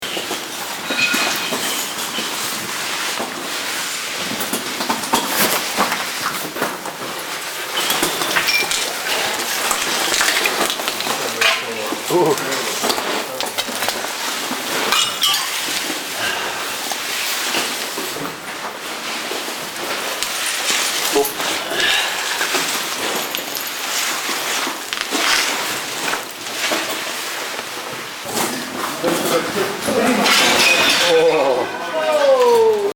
Take a listen to the sounds of what its like to crawl through an englacial water channel.
ice_cave_sounds.mp3